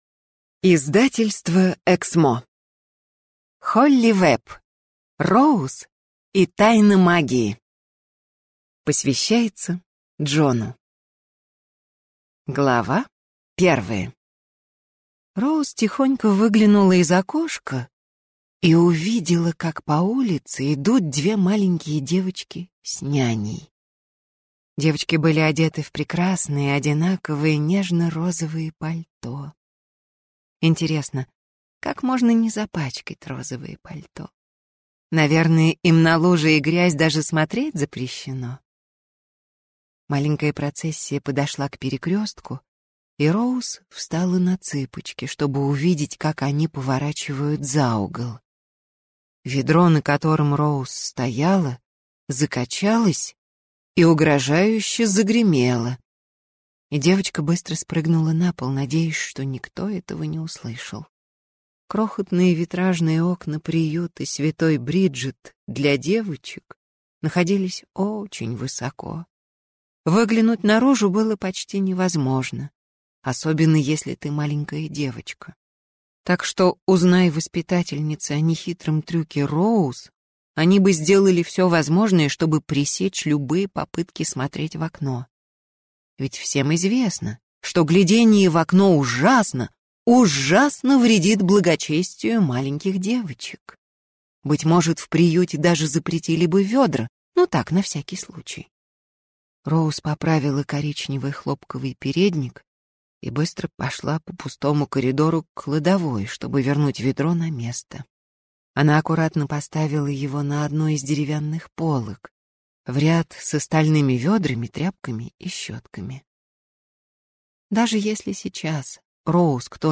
Аудиокнига Роуз и тайна магии | Библиотека аудиокниг
Прослушать и бесплатно скачать фрагмент аудиокниги